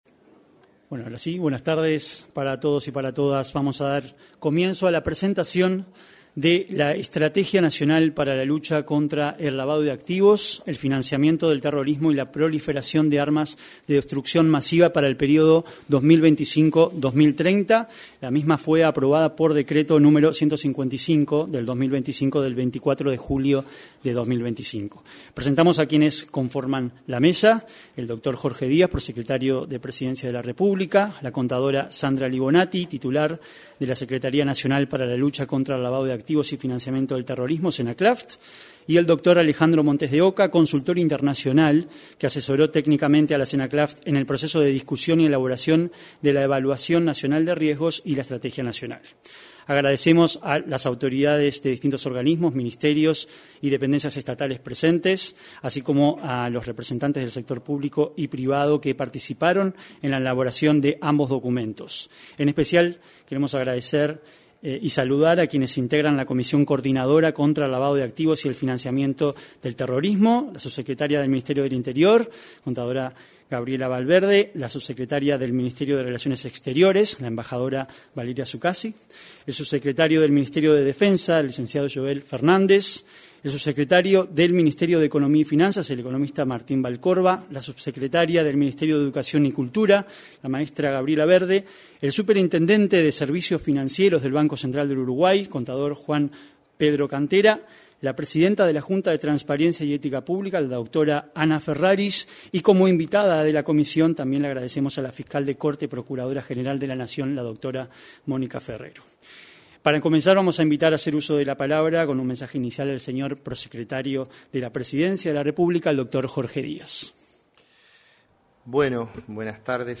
En la presentación de la Estrategia Nacional contra el Lavado de Activos y Financiamiento del Terrorismo, se expresaron el prosecretario de la